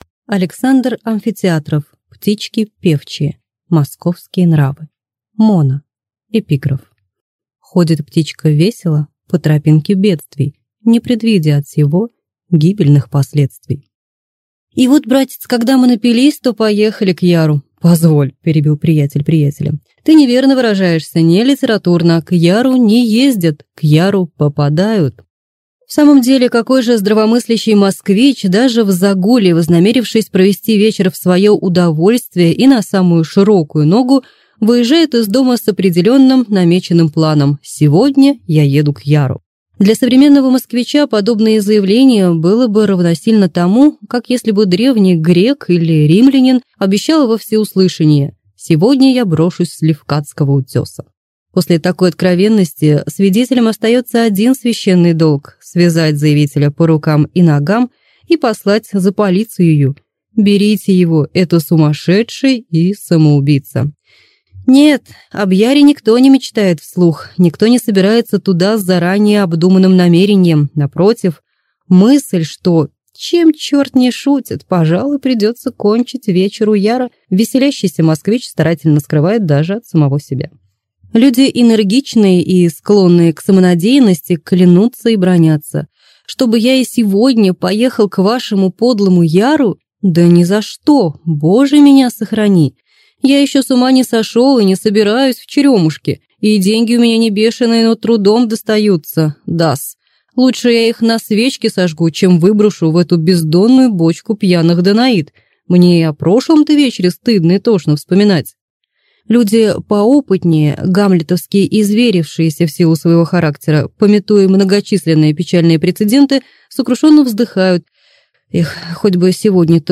Аудиокнига Птички певчие | Библиотека аудиокниг